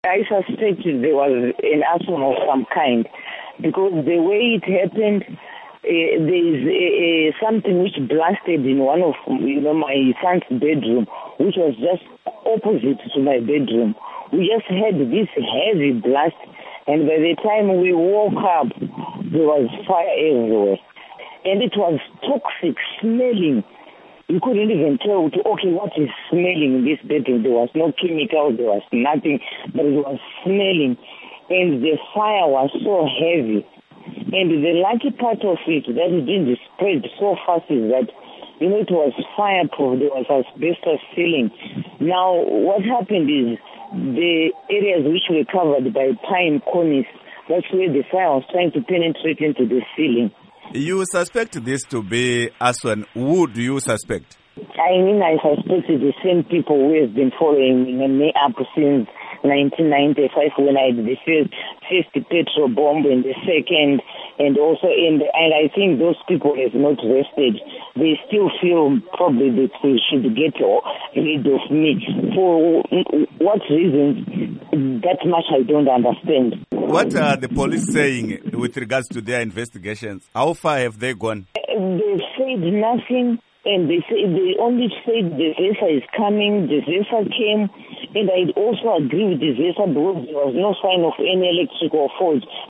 Interview With Margaret Dongo